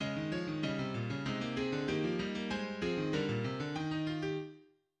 • 複音音樂（polyphony）：首音樂由兩段或者以上各自能夠獨立存在嘅旋律組成，當中冇任何一段明顯係「主旋律」，例如下面呢段係《十二平均律鋼琴曲集》嘅《A♭ 17 號賦格曲》嘅其中一段噉，上下兩行五線譜嘅四個聲部，每部都係一段旋律，而且冇任何一段旋律零舍突出做主旋律[5]